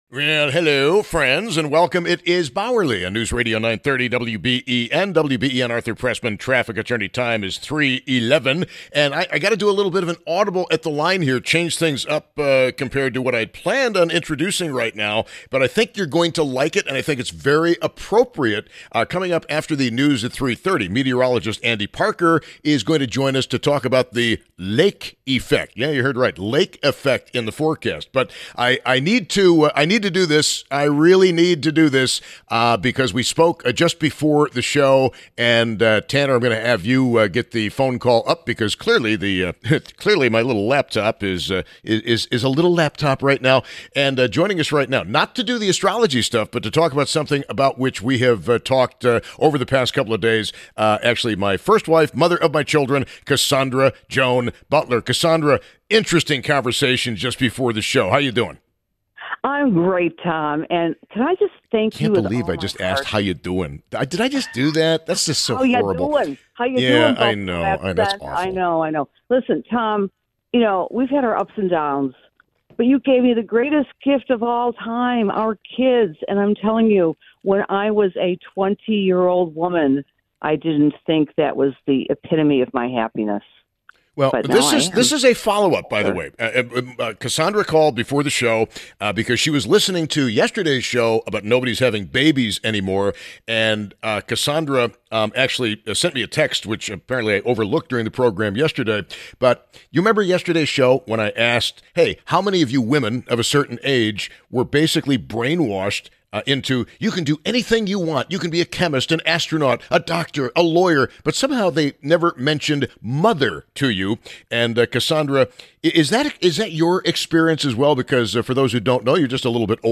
(It’s from a Wednesday radio show). It’s supposed to be Claude telling to the radio show’s host’s ex-wife what the “goal” of AIs with respect to human beings is.